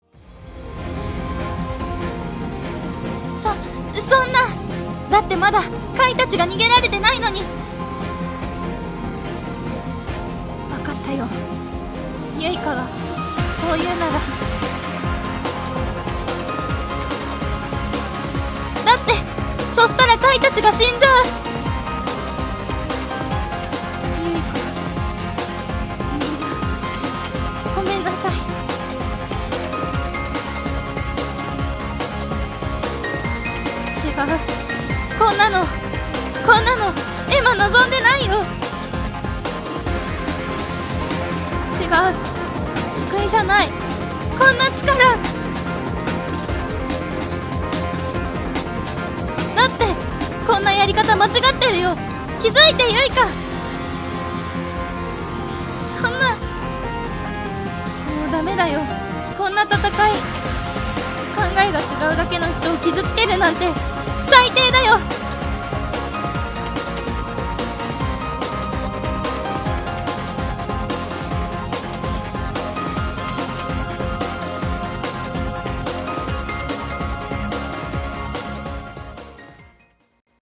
声劇課題